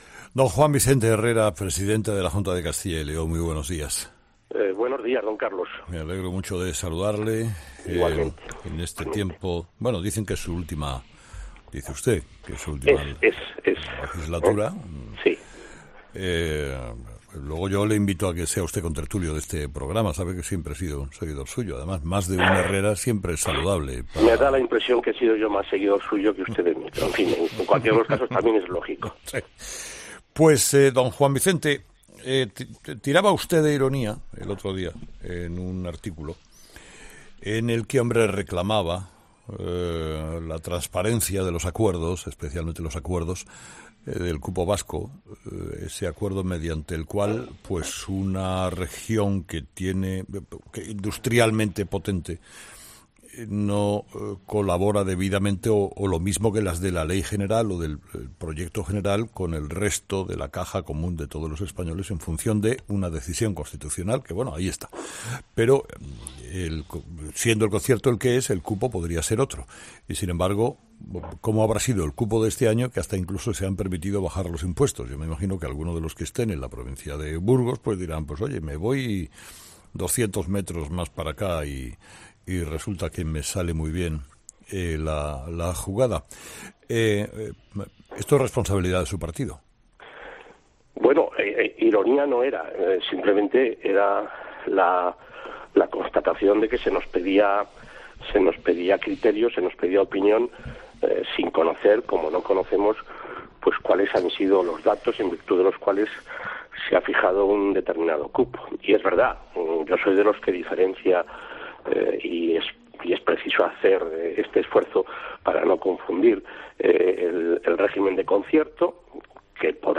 ESCUCHA LA ENTREVISTA COMPLETA | JUAN VICENTE HERRERA EN 'HERRERA EN COPE'